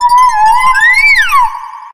infinitefusion-e18 / Audio / SE / Cries / GLACEON.ogg
GLACEON.ogg